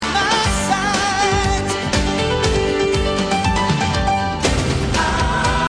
piano riff